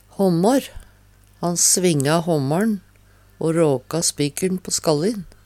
håmmår - Numedalsmål (en-US)